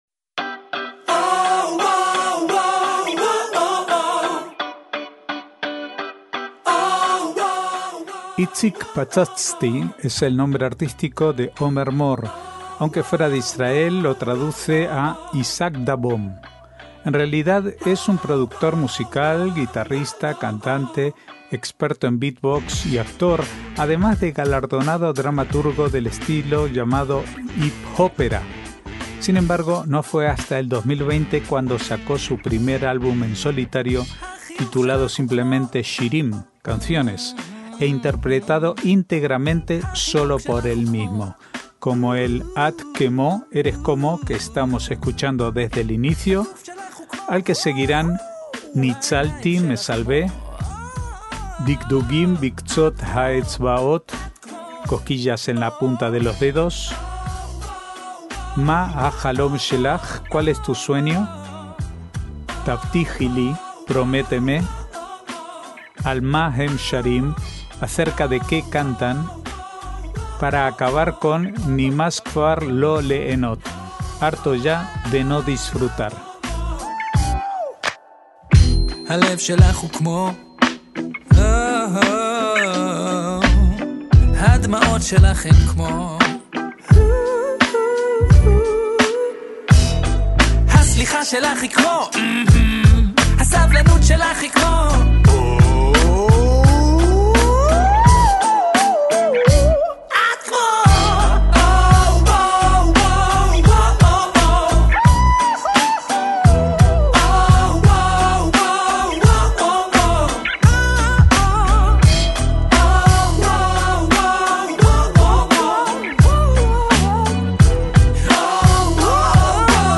MÚSICA ISRAELÍ